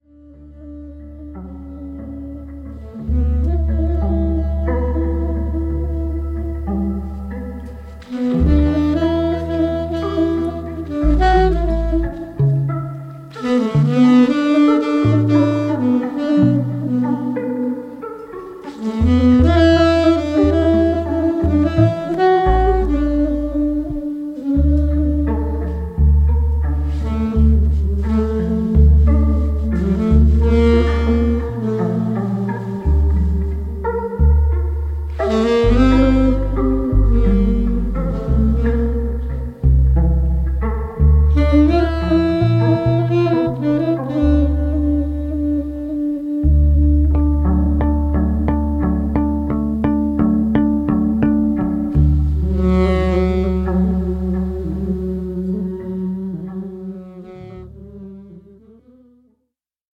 ホーム ｜ JAZZ